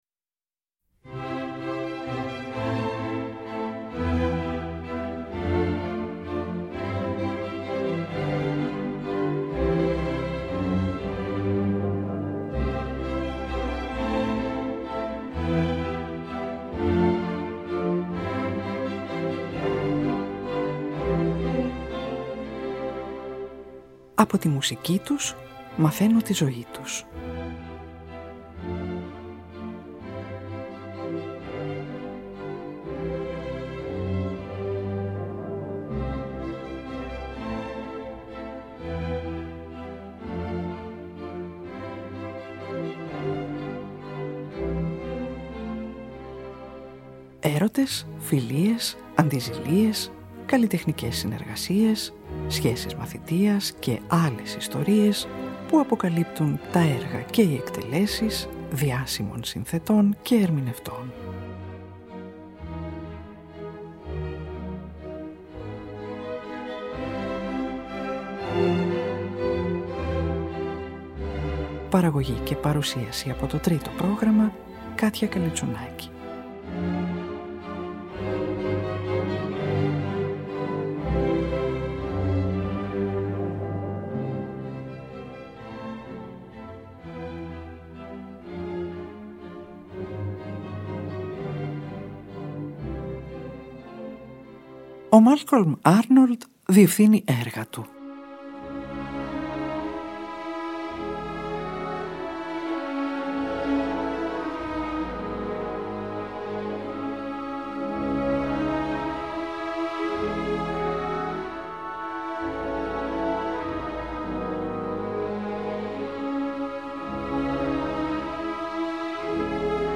Κοντσέρτου για Κιθάρα